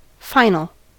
final: Wikimedia Commons US English Pronunciations
En-us-final.WAV